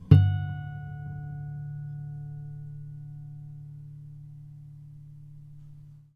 harmonic-07.wav